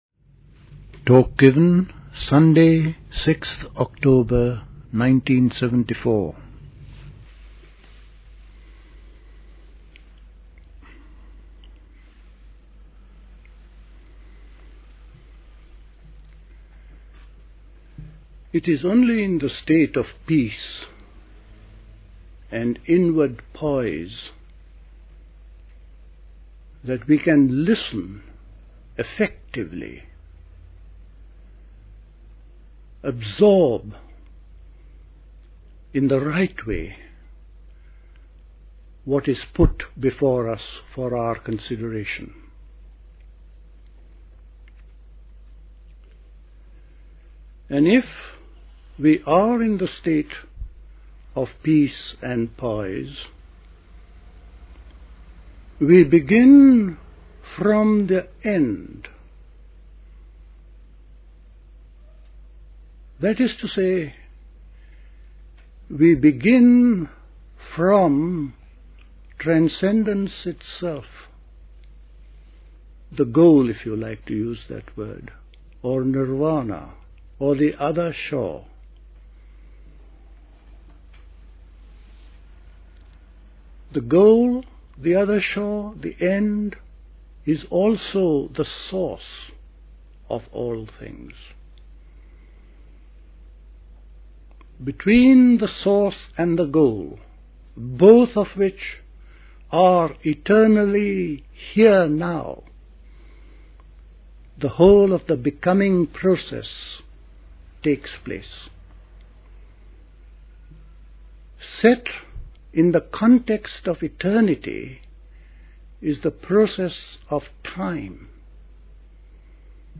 at Dilkusha, Forest Hill, London on 6th October 1974